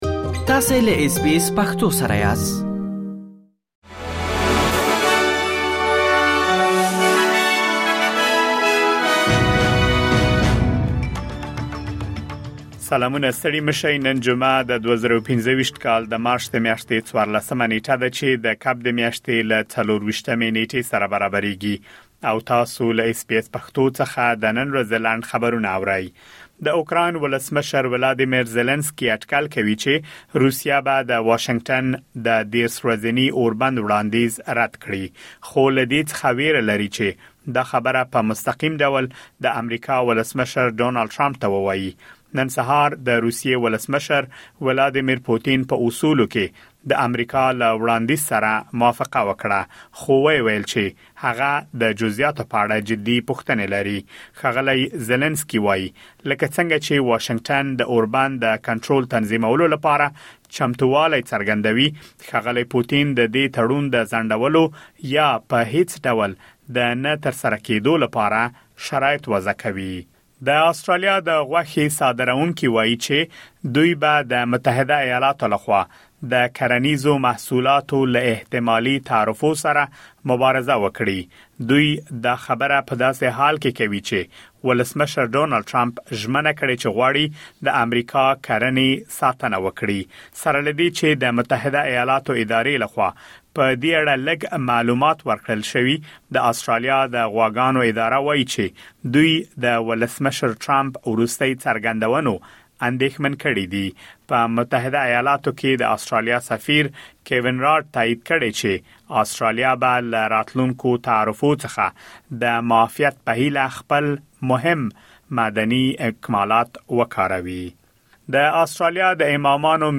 د اس بي اس پښتو د نن ورځې لنډ خبرونه دلته واورئ.